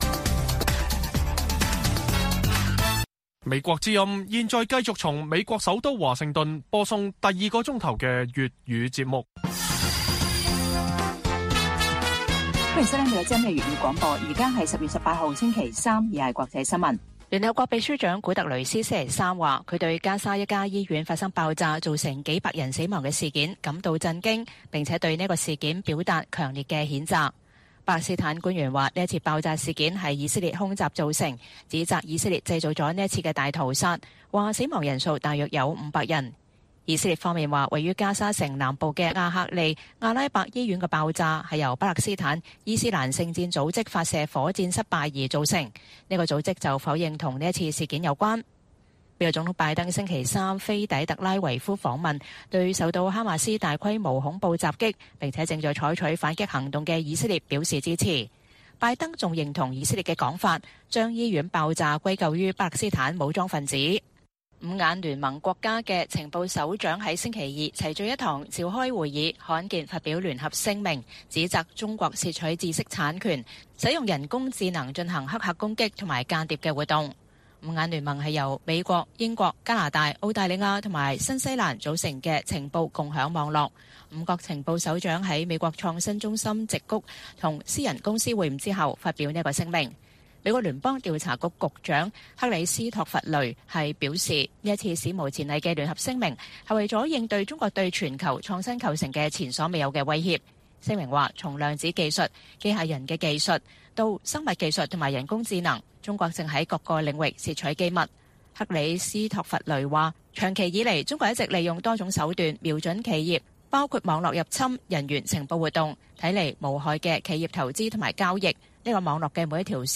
粵語新聞 晚上10-11點: 聯合國秘書長強烈譴責加沙醫院爆炸事件